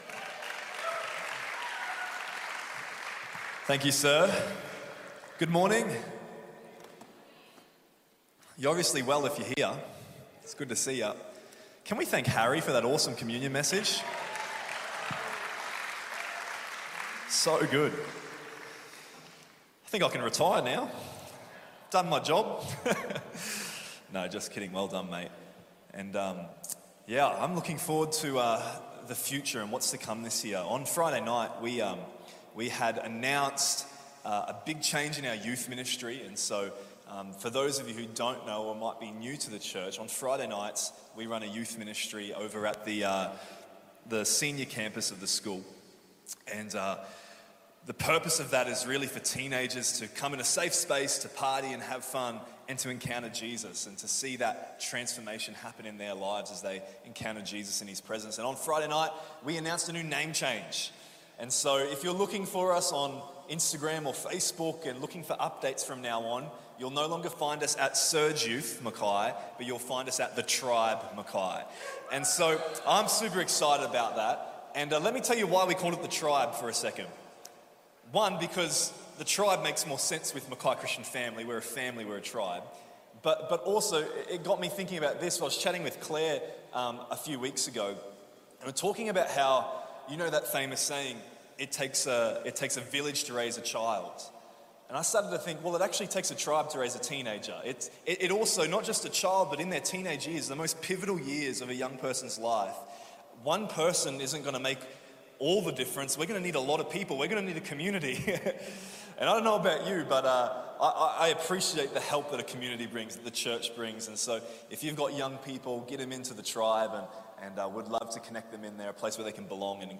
NB: we had some issues with the recording this Sunday. it only last 2-3 minutes.
Weekly Sermons